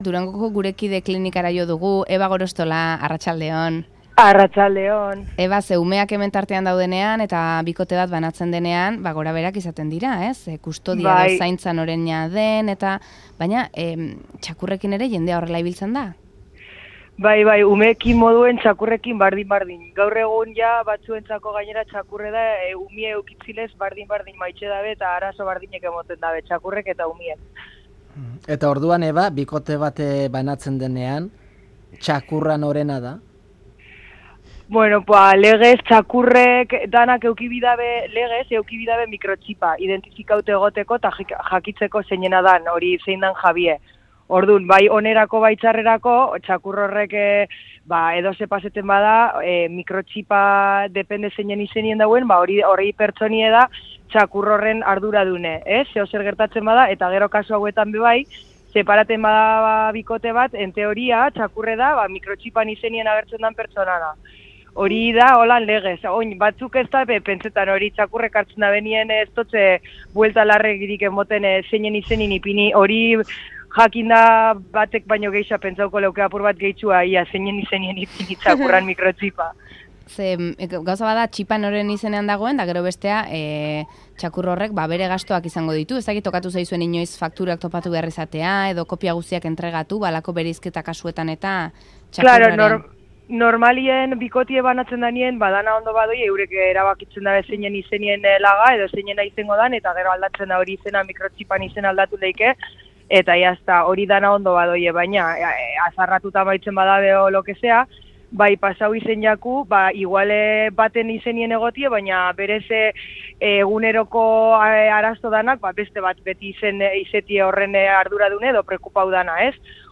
Zaintza partekatua, txakurrekin? Gure Kide klinikara deitu dugu.